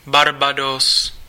Ääntäminen
Vaihtoehtoiset kirjoitusmuodot (vanhahtava) Barbadoes Ääntäminen US UK : IPA : /bɑː(ɹ)ˈbeɪ.dɒs/ US : IPA : /bɑɹˈbeɪ.doʊs/ Lyhenteet ja supistumat (laki) Barb.